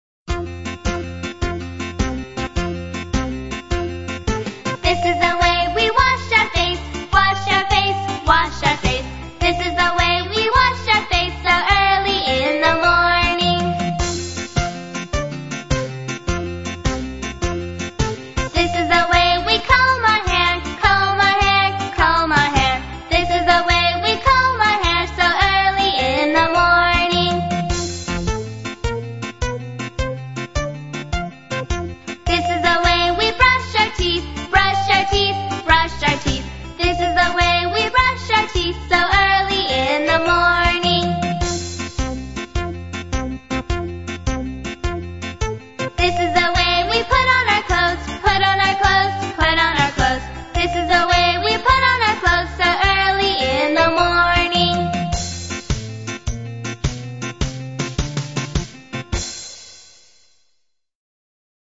在线英语听力室英语儿歌274首 第229期:This Is the Way的听力文件下载,收录了274首发音地道纯正，音乐节奏活泼动人的英文儿歌，从小培养对英语的爱好，为以后萌娃学习更多的英语知识，打下坚实的基础。